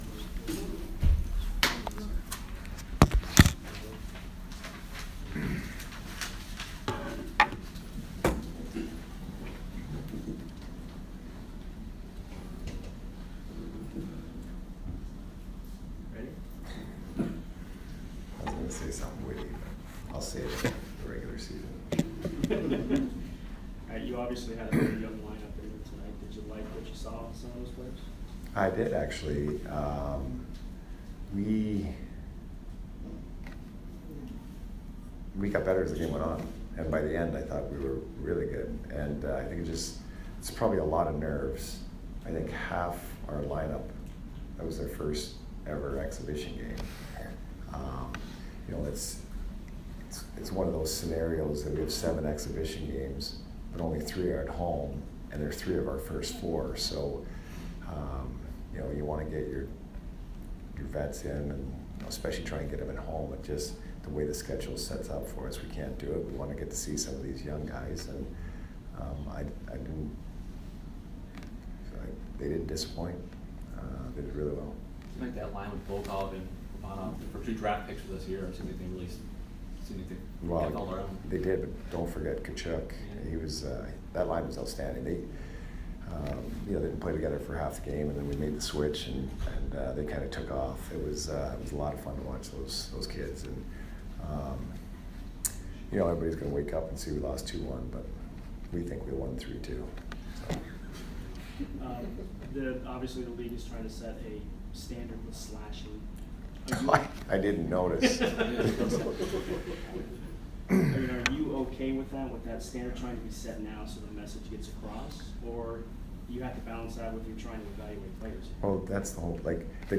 Jon Cooper Postgame Press Conference